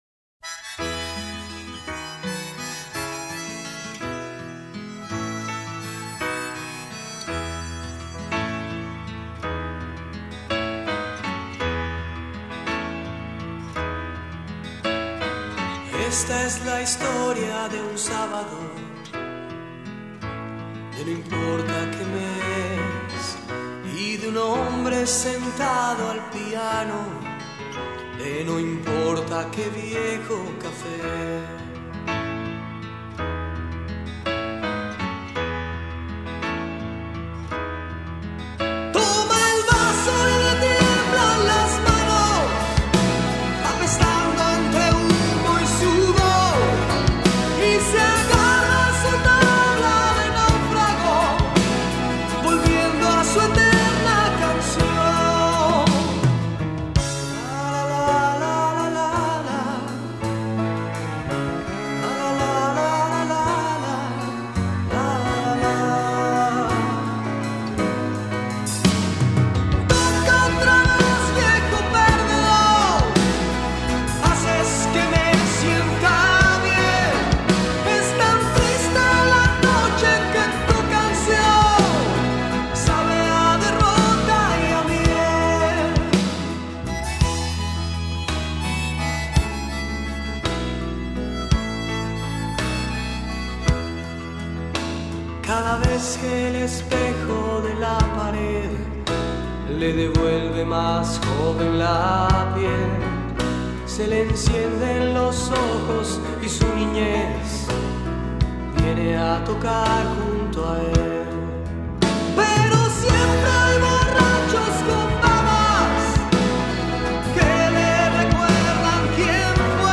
Genre: Español.